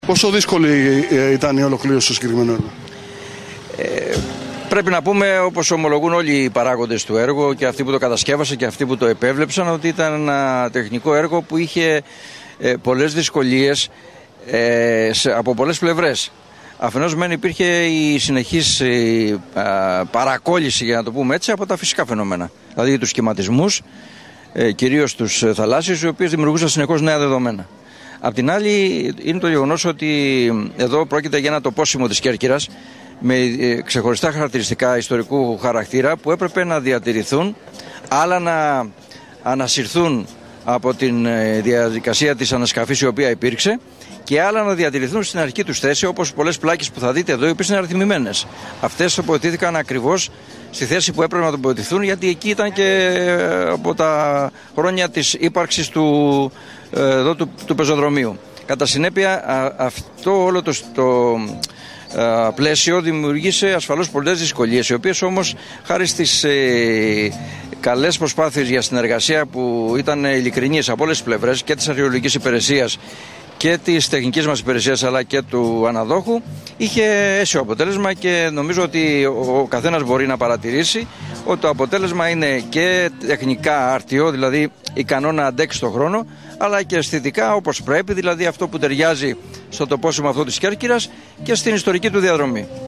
ΘΕΟΔΩΡΟΣ ΓΑΛΙΑΤΣΑΤΟΣ
ΑΠΕΡΧΟΜΕΝΟΣ ΠΕΡΙΦΕΡΕΙΑΡΧΗΣ ΙΟΝΙΩΝ ΝΗΣΩΝ